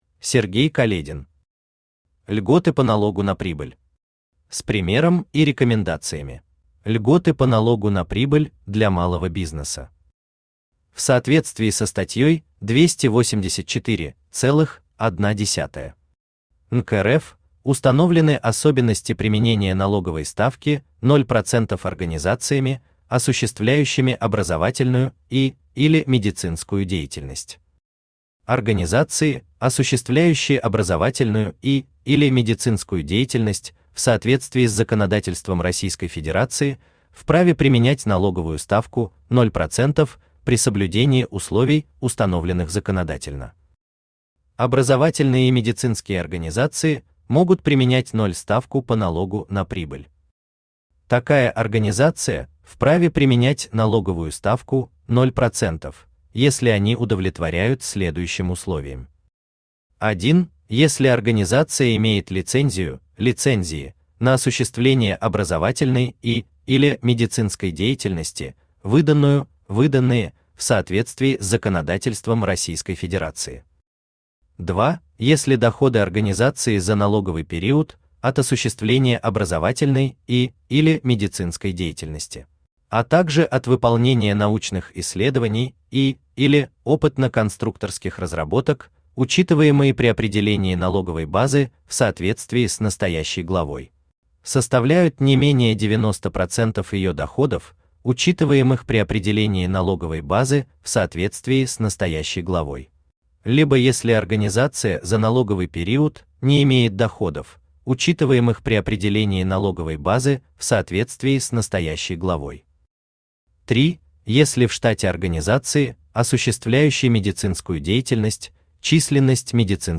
Аудиокнига Льготы по налогу на прибыль. С примером и рекомендациями | Библиотека аудиокниг